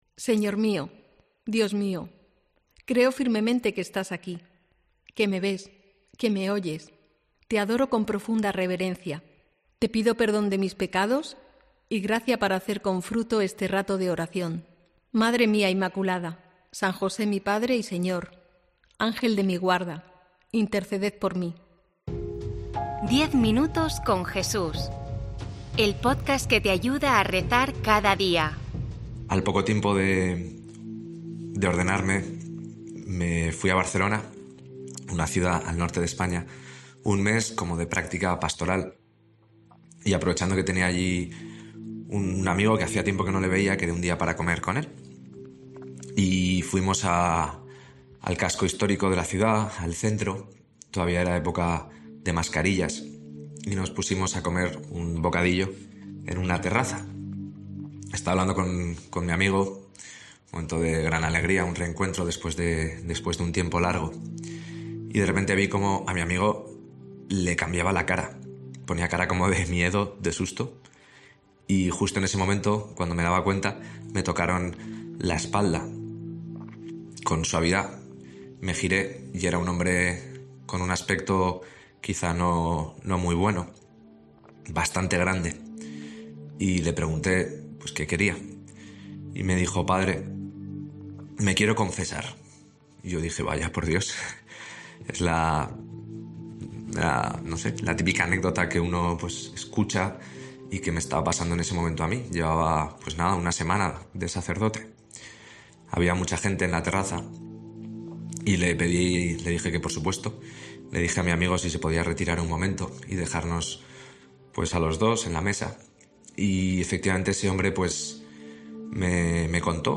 Junto con la meditación, el sacerdote propone escuchar un villancico en el que se pregunta a la Virgen María si en aquel pesebre, siendo un pequeño niño indefenso, sabía y reconocía en aquella criatura al mismo Dios, al mismo Jesucristo.